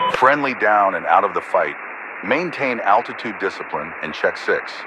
Radio-commandFriendlyDown5.ogg